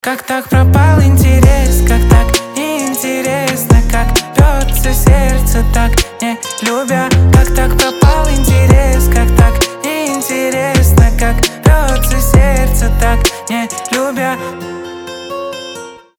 • Качество: 320, Stereo
гитара
мужской голос
RnB